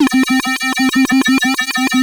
OSCAR 14 C1.wav